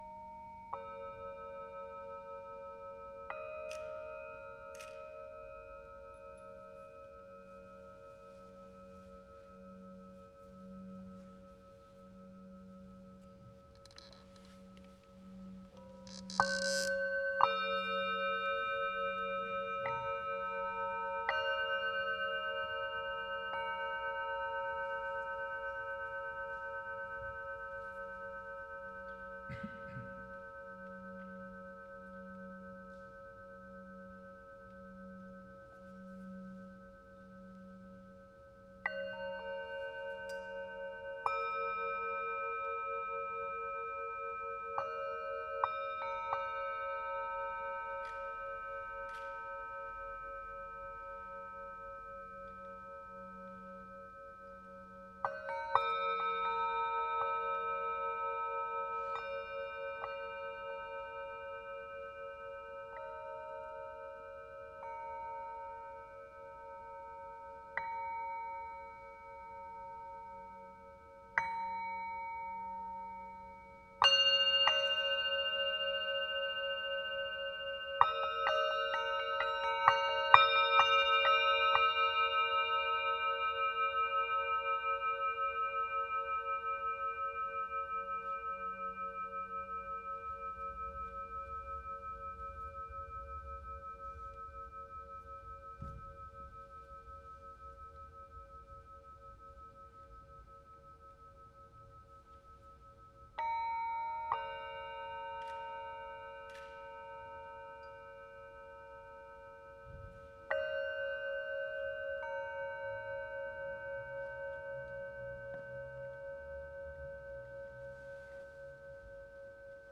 🎧 Break Sonore (9 min) Pour une expérience optimale, nous vous recommandons d’utiliser un casque ou des écouteurs.
Extrait bain sonore en live